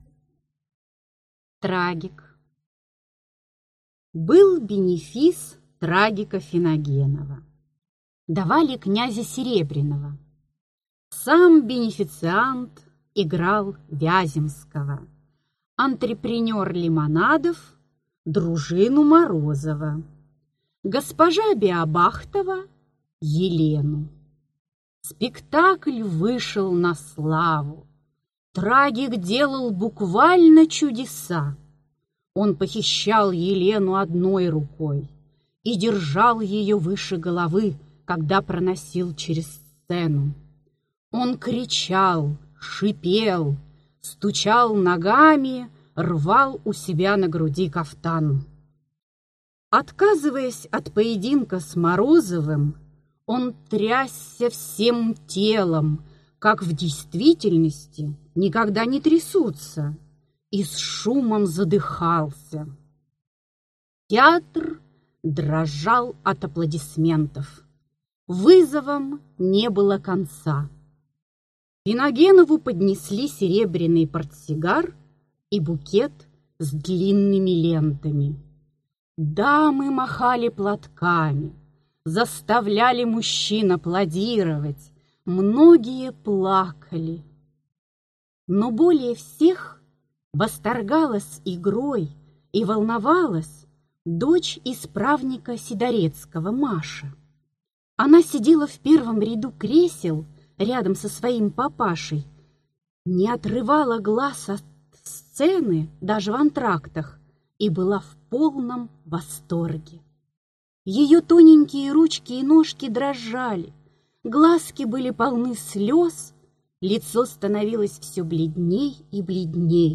Аудиокнига Случай из судебной практики.